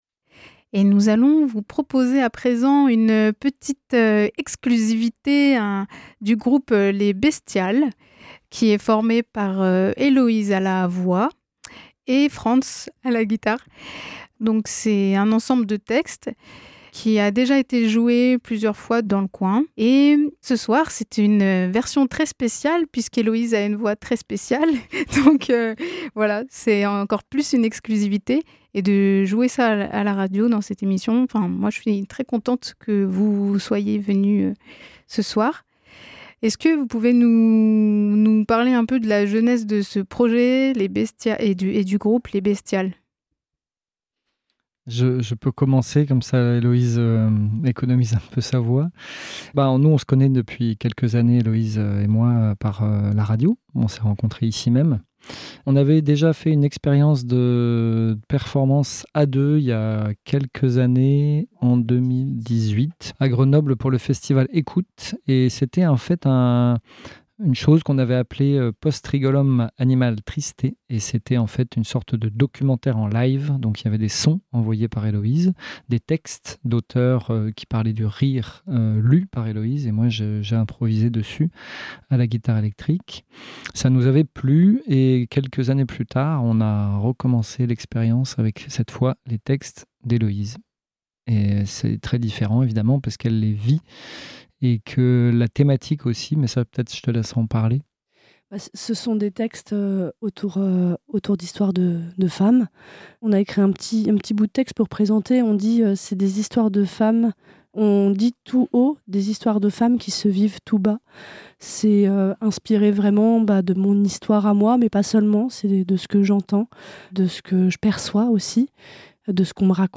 Guitare électrique
Quand Les Bestiales jouent en live à la radio et sont interviewées sur leur travail…